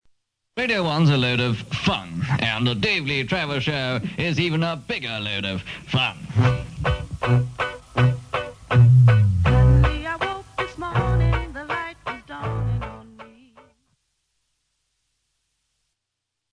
DLT Ident by Keith Skues 1969